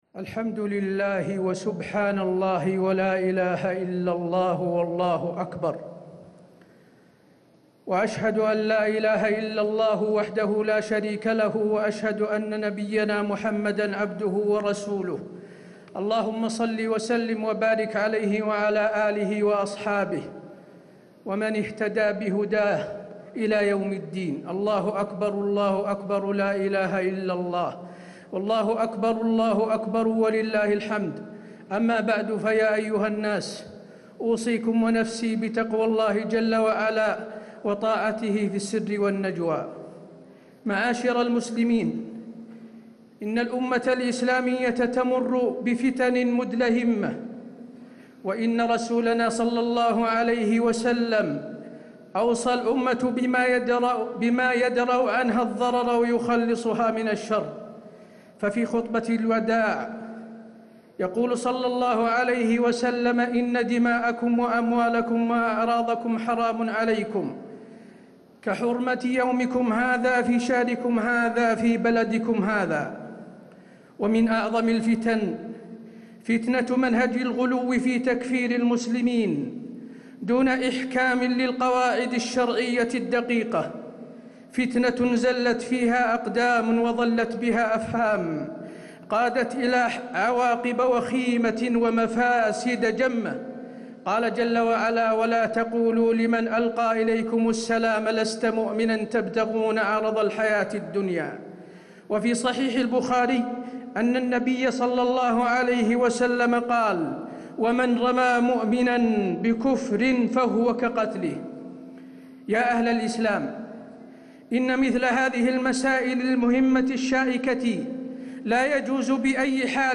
خطبة عيد الأضحى - المدينة - الشيخ حسين آل الشيخ
المكان: المسجد النبوي